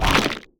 poly_shoot_stone.wav